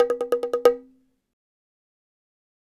Bongo Sixtuplet.wav